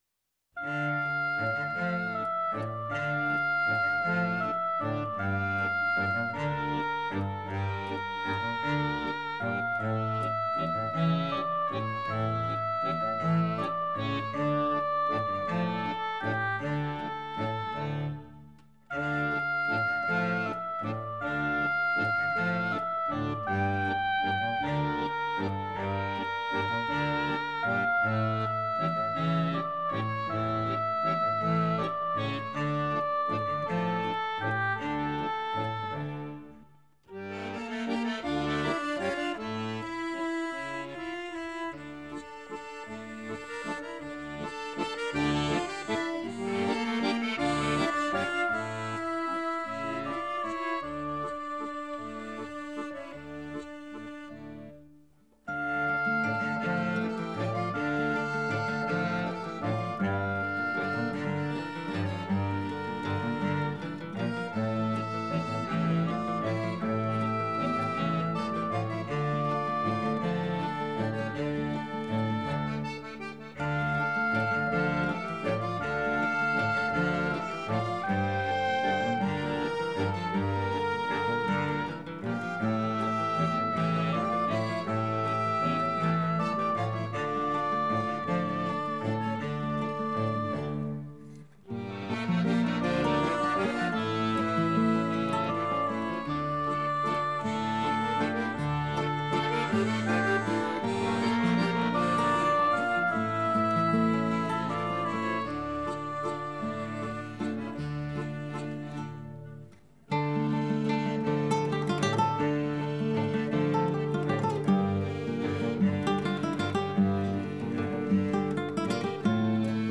Genre: Fado, Ballad